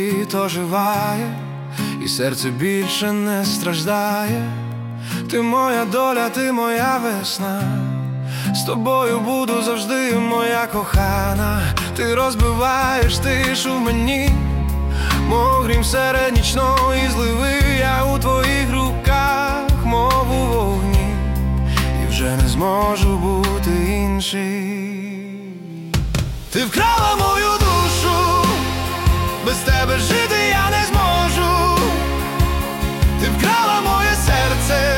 Pop Hip-Hop Rap
Жанр: Хип-Хоп / Рэп / Поп музыка / Украинские